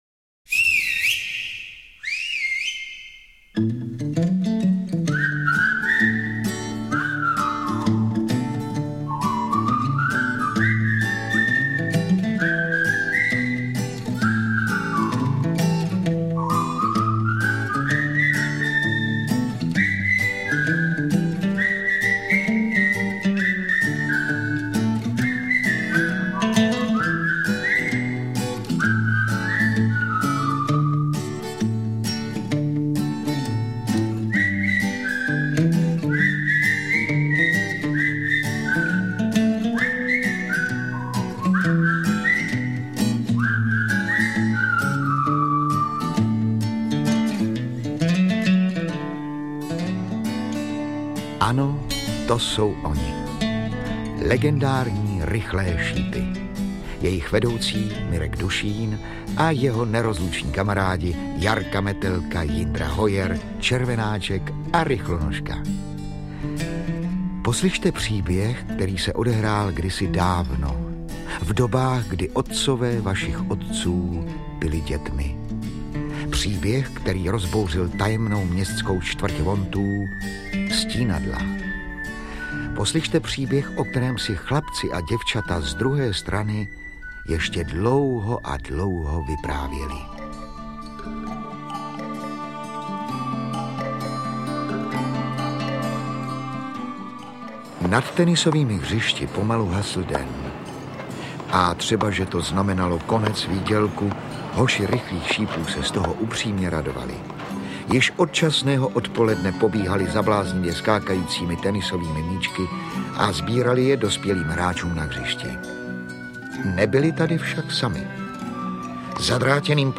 Dramatizace tří zásadních příběhů, patřících k vrcholným dílům Jaroslava Foglara.